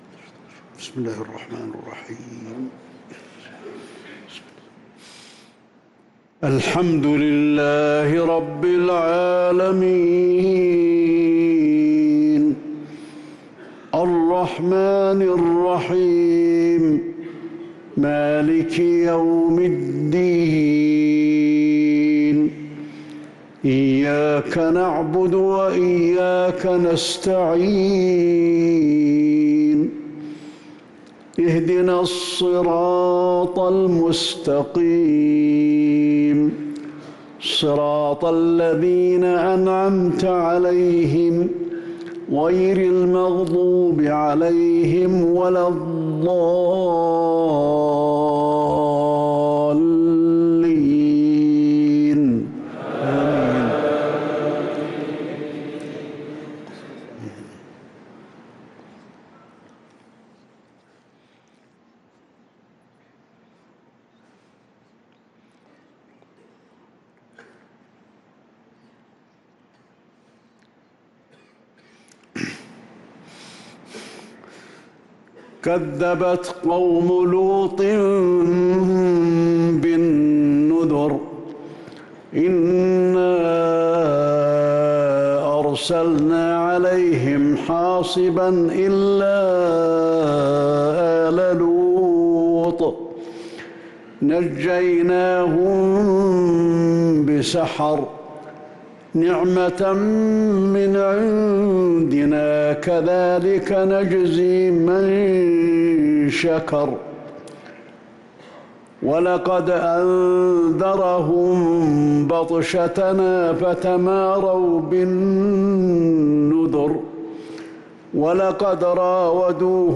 صلاة العشاء للقارئ علي الحذيفي 25 ذو الحجة 1444 هـ
تِلَاوَات الْحَرَمَيْن .